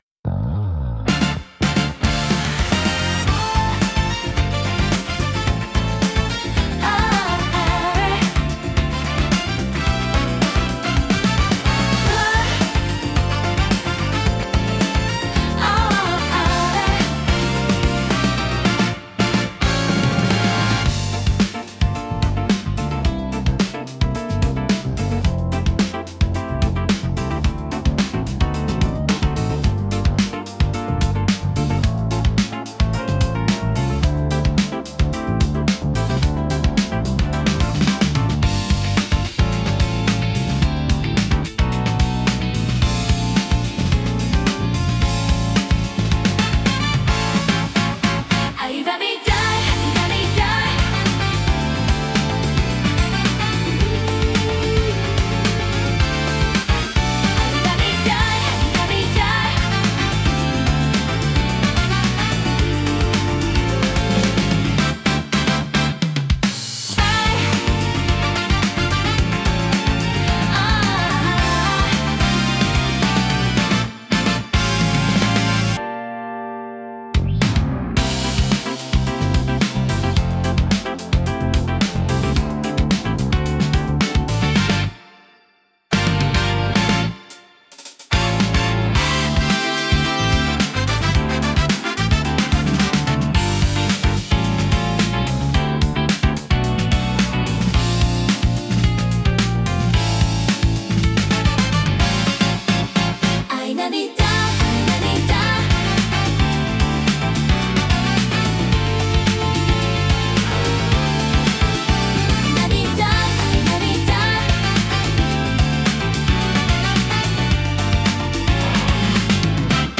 聴く人に洗練された印象を与えつつ、リラックスと活気のバランスを巧みに表現します。